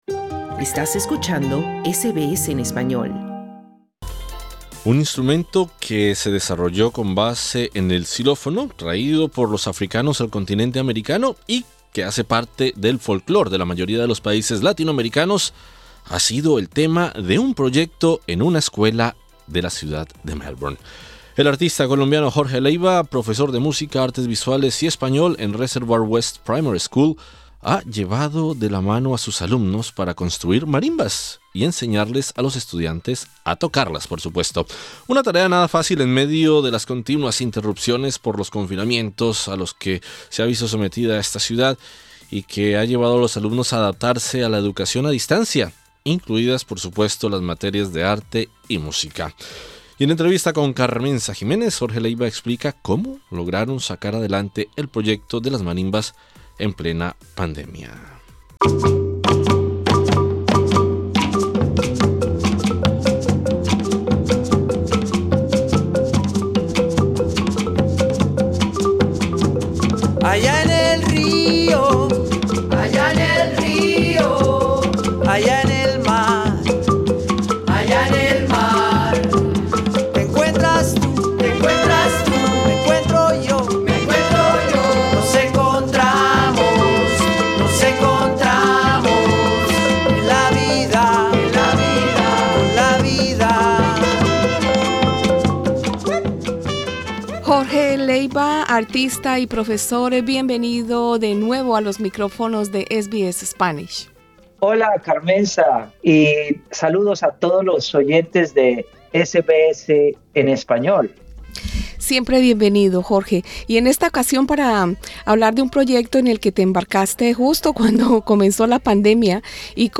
En entrevista con SBS Spanish